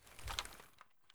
mp133_close.ogg